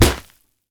punch_grit_wet_impact_04.ogg